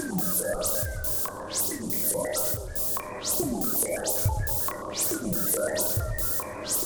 STK_MovingNoiseC-140_02.wav